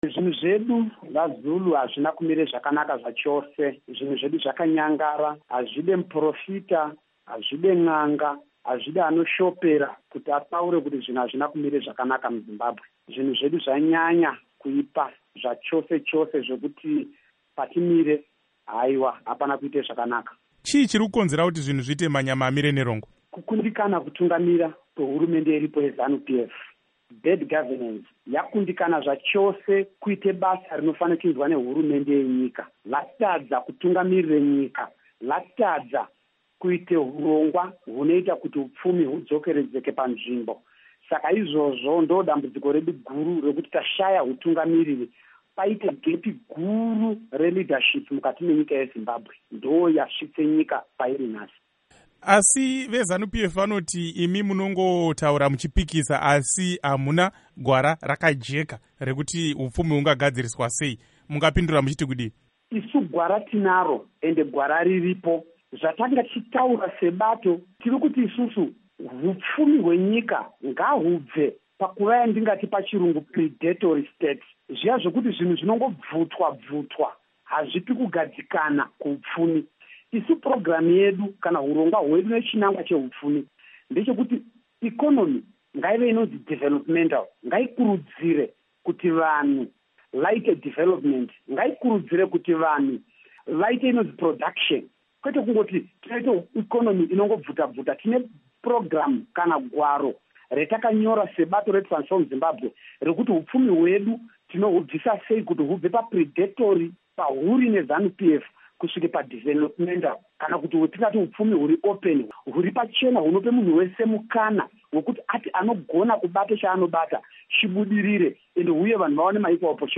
Hurukuro naVaJacob Ngarivhume